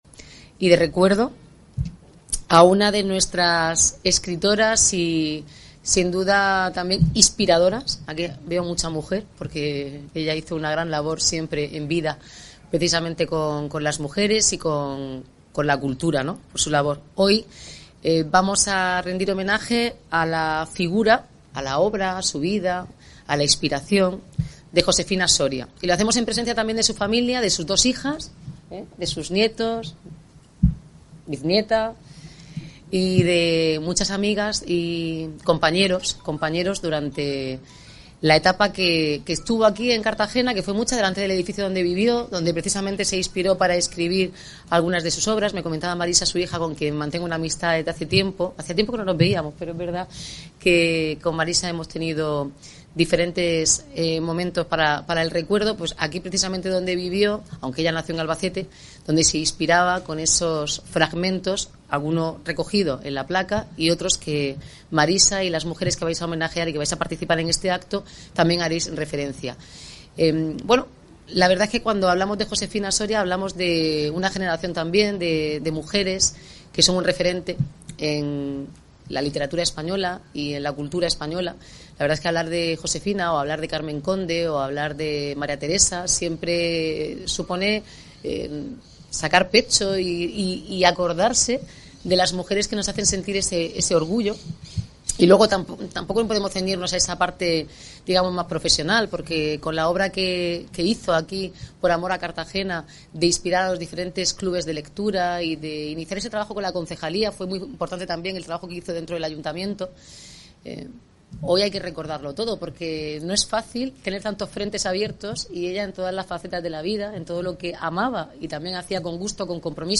El acto, presidido por la alcaldesa de Cartagena, ha reunido a decenas de amigos y seguidores de la poetisa en la Muralla del Mar, n� 19
Declaraciones de la alcaldesa